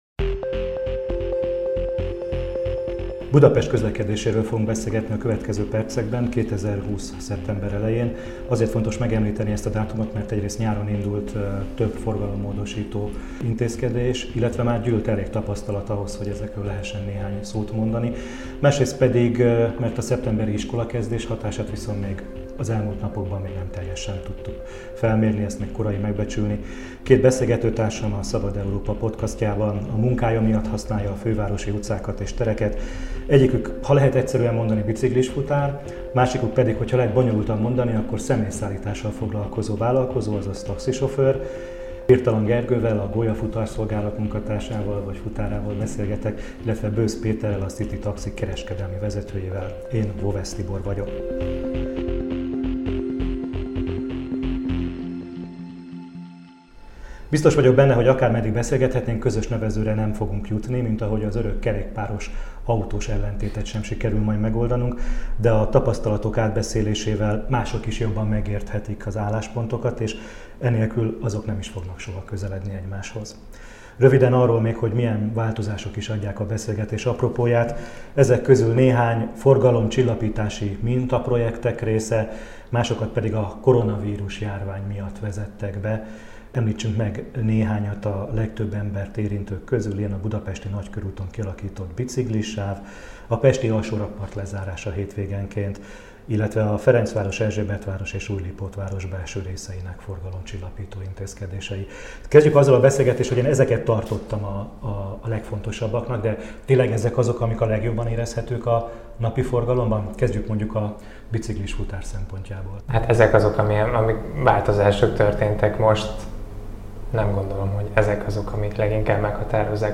„Dzsungelharc van” – egy kerékpáros és egy taxis a budapesti közlekedésről